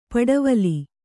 ♪ paḍavala